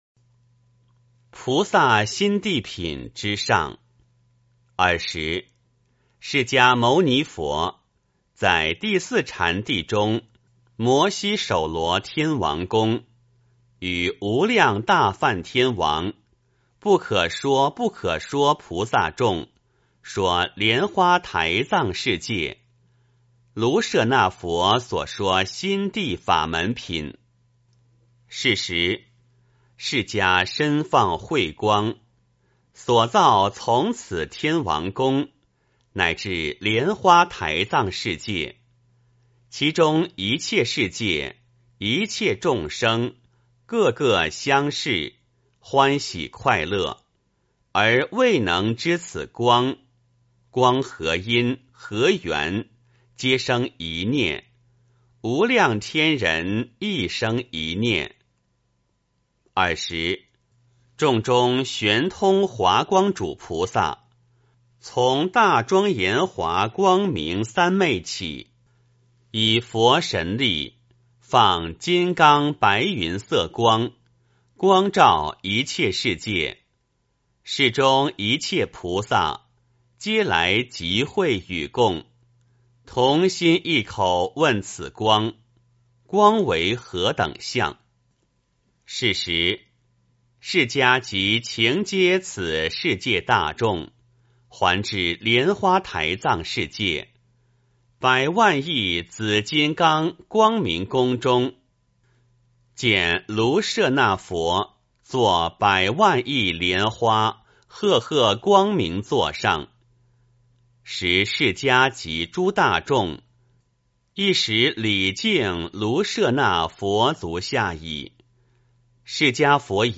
梵网经-菩萨心地品之上 诵经 梵网经-菩萨心地品之上--未知 点我： 标签: 佛音 诵经 佛教音乐 返回列表 上一篇： 佛顶尊胜陀罗尼经-3 下一篇： 梵网经-十金刚心 相关文章 药师佛心咒--男女合唱 药师佛心咒--男女合唱...